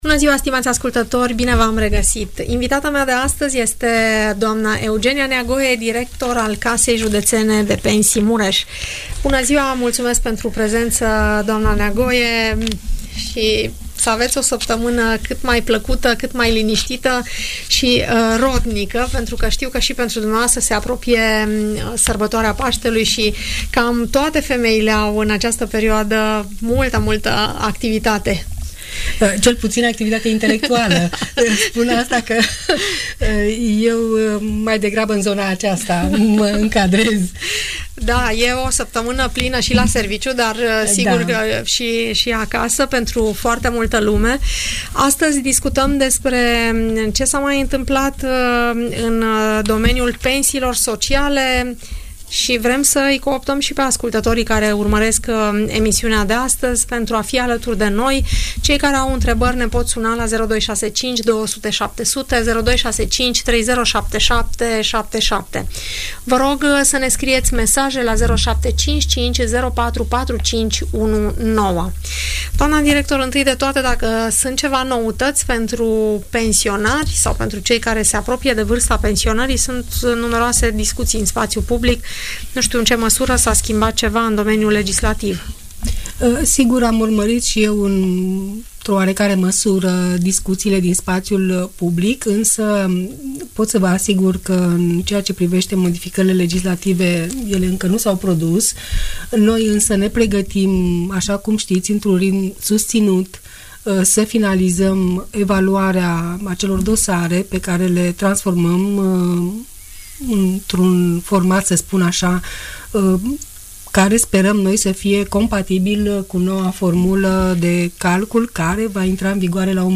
Audiență radio cu întrebări și răspunsuri despre toate tipurile de pensii, în emisiunea „Părerea ta” de la Radio Tg Mureș.